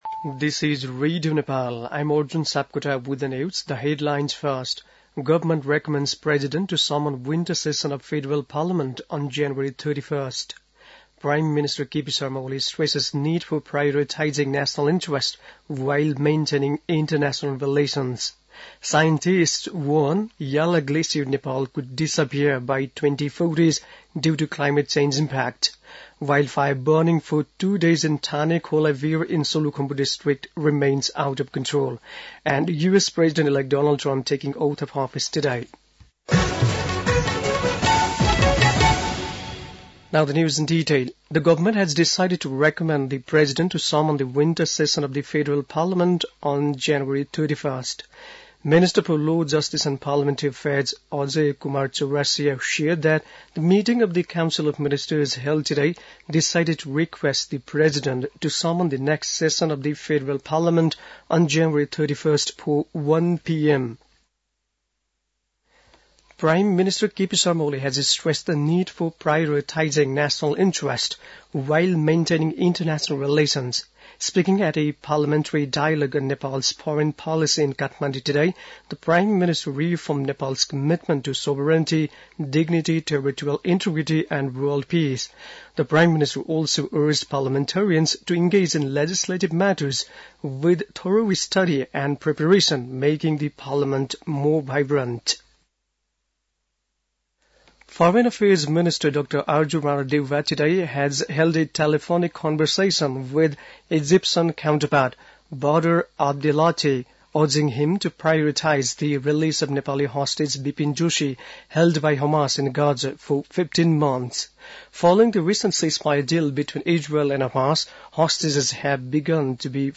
बेलुकी ८ बजेको अङ्ग्रेजी समाचार : ८ माघ , २०८१
8-pm-english-news-10-07.mp3